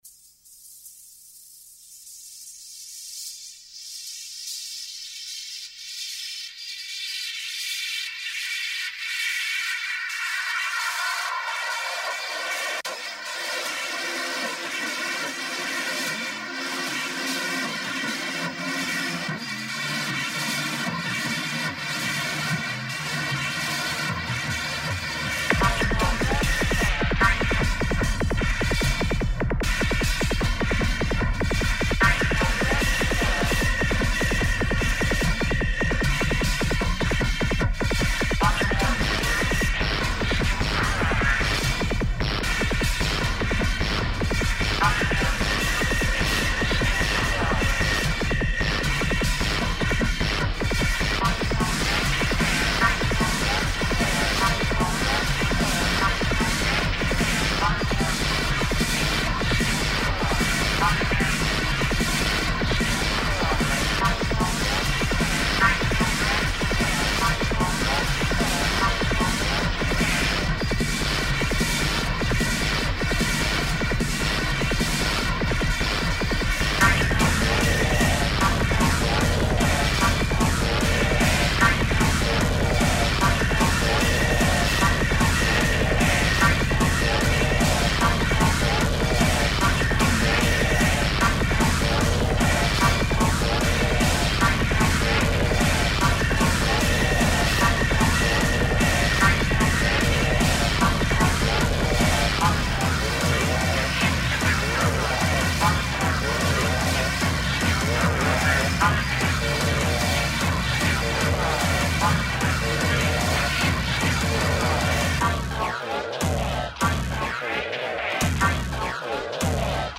y'a un autre morceau, plus indus/noize, ici :
pas vraiment pareil dans l'esprit, plus violent et torturé, m'en direz des nouvelles !